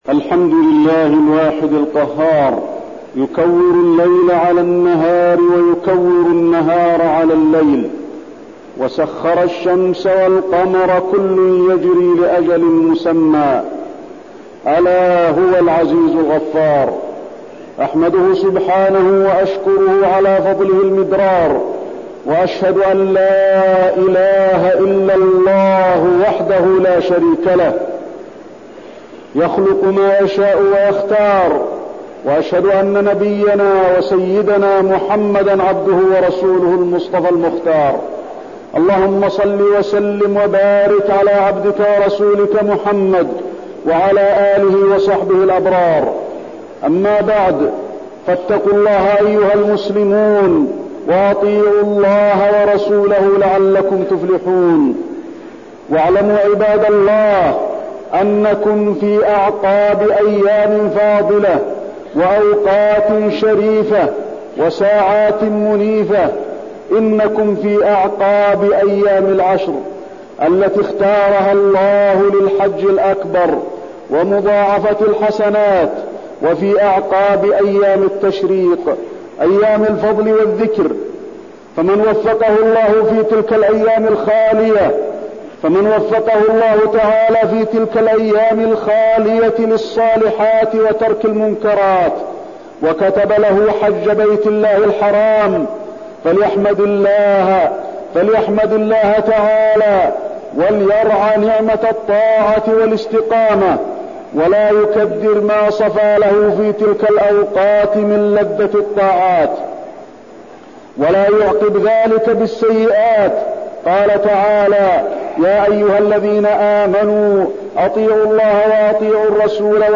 تاريخ النشر ١٨ ذو الحجة ١٤٠٩ هـ المكان: المسجد النبوي الشيخ: فضيلة الشيخ د. علي بن عبدالرحمن الحذيفي فضيلة الشيخ د. علي بن عبدالرحمن الحذيفي فتنة أعداء الإسلام في الحج